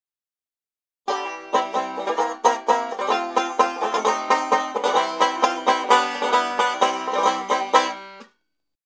BANJO STUDENT
N�STROJ S PLN�M A JASN�M ZVUKEM, BL͎�C�M SE MASTERTONE KONSTRUKCI